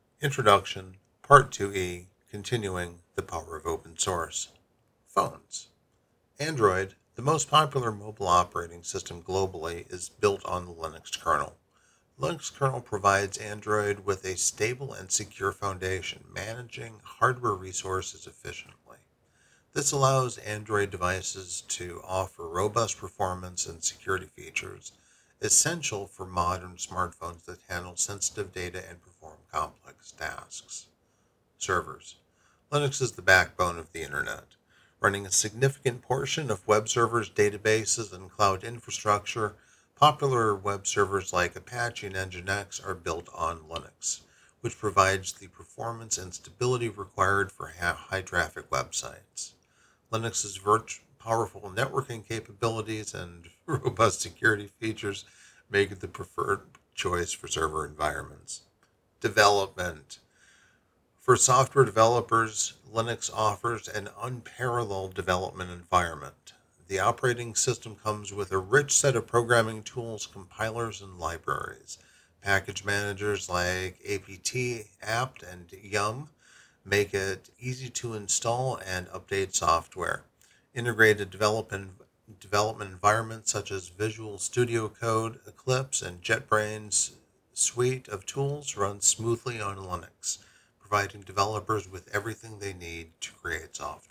'Audiobook